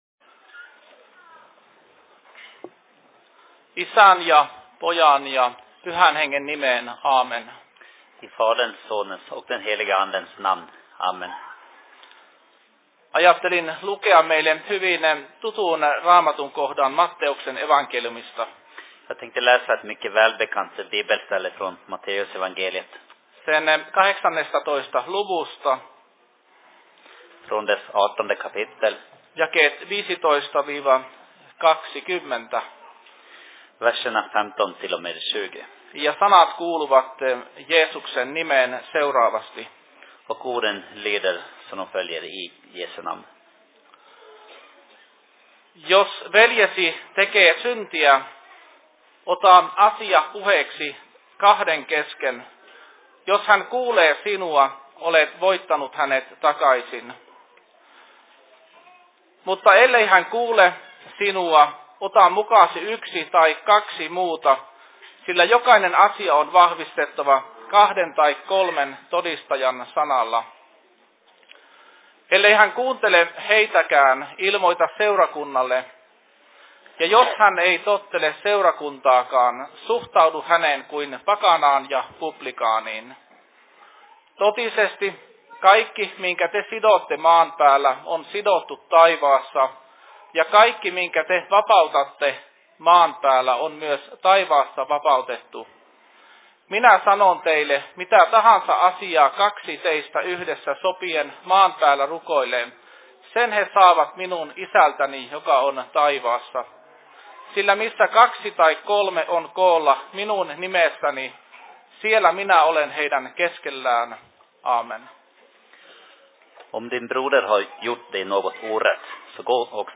Påskmötet/Pääsiäisseurat/Fi Se Predikan I Dalarnas Fridsförening 02.04.2018
Simultaanitulkattu